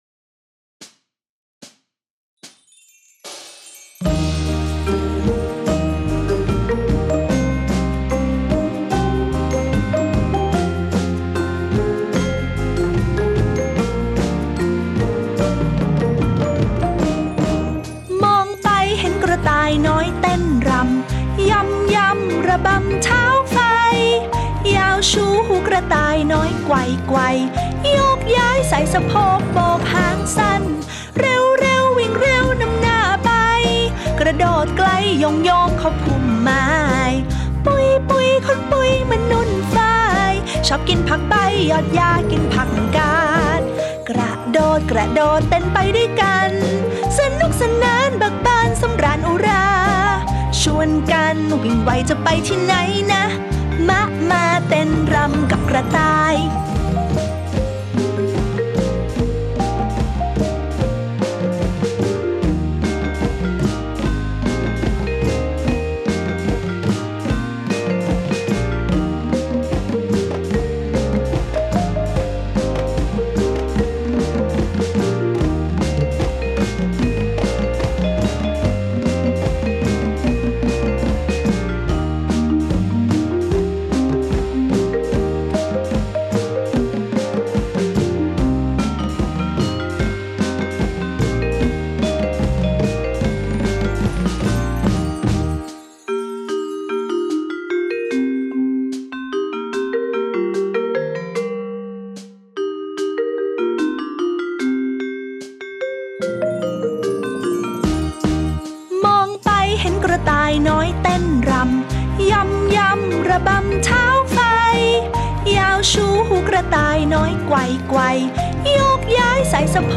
ไฟล์เพลงพร้อมเสียงร้อง